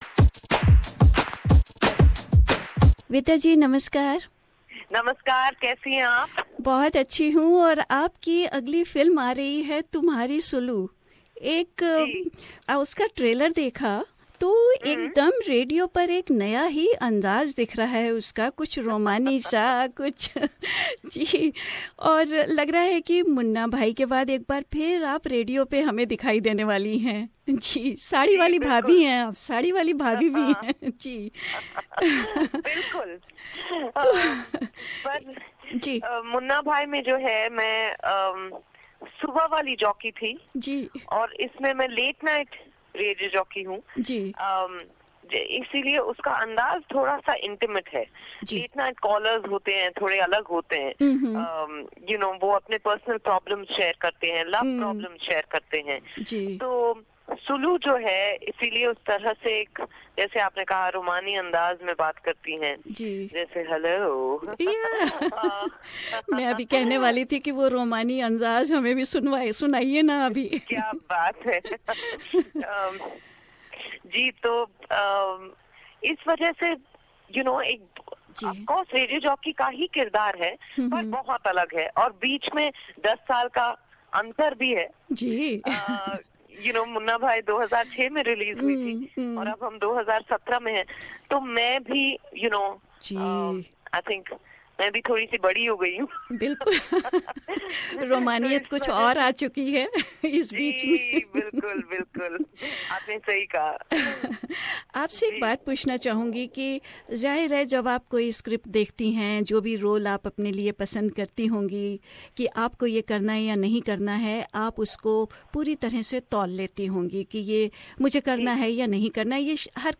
Speaking with SBS Hindi, Ms Vidya Balan said that it was a very comfortable set up for the film shoot.